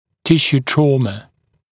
[‘tɪʃuː ‘trɔːmə] [-sjuː][‘тишу: ‘тро:мэ] [-сйу:]травма тканей